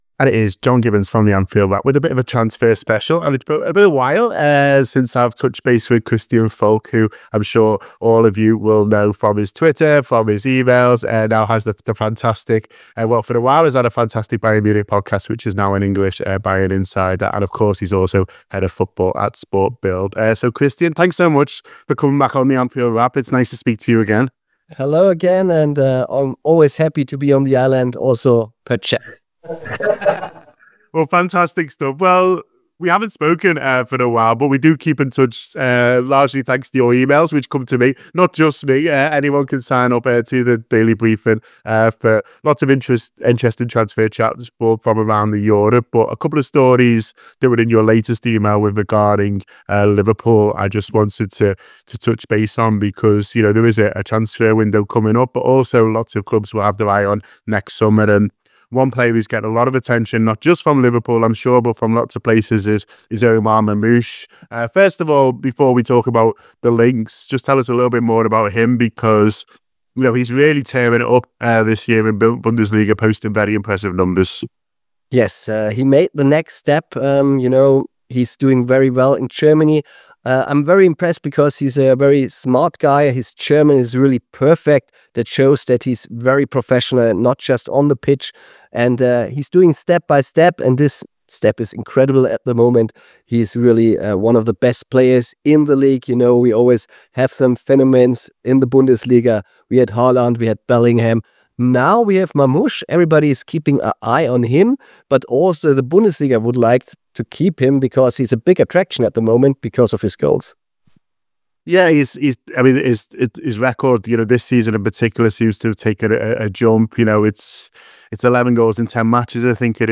Below is a clip from the show – subscribe for more on Omar Marmoush and other Liverpool transfer news…